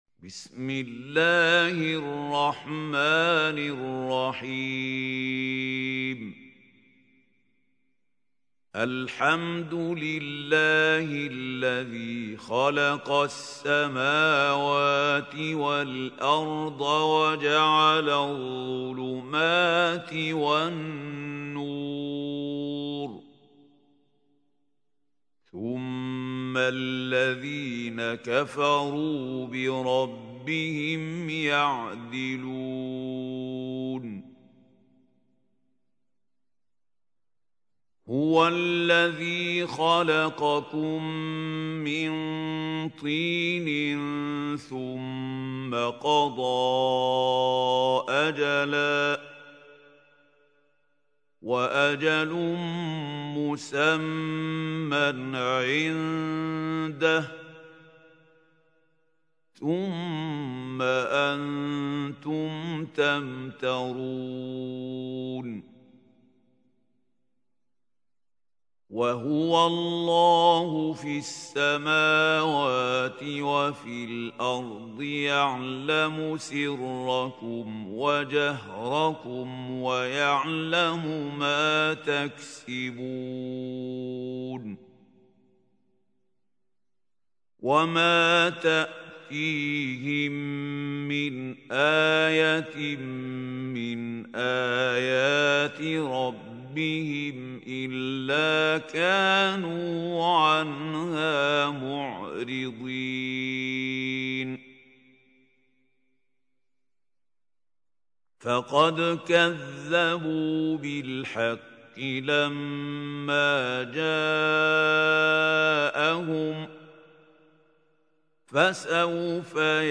سورة الأنعام | القارئ محمود خليل الحصري